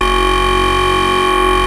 OSCAR 15 G#1.wav